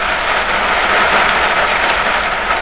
rain
surface_rain.ogg